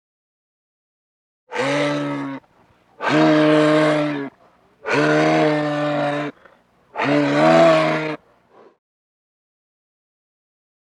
animal
Moose Calls